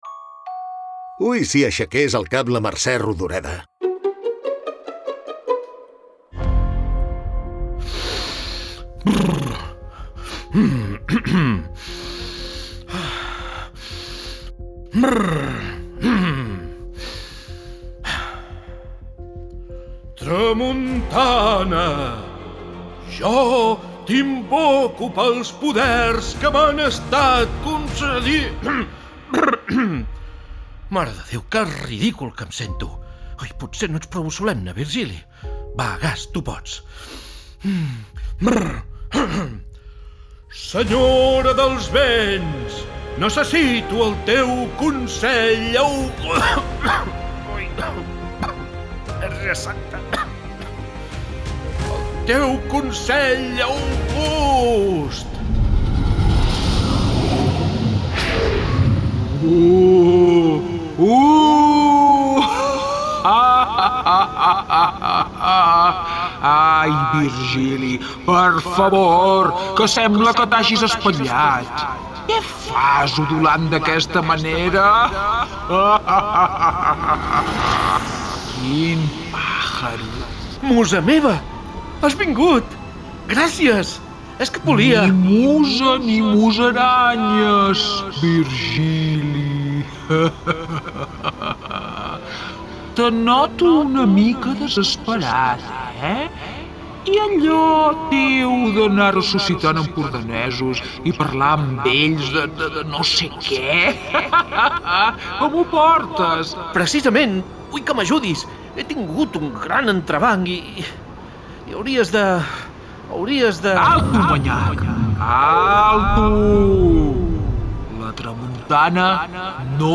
Vol parlar de la relació de la comarca amb la capital, d’aquest anar i venir constant des que la Costa Brava es va posar de moda entre els capitolins (Foix, Riba, Segarra, etc..). Rodoreda conversarà amb la Tramuntana en un to… diguem-ne íntim.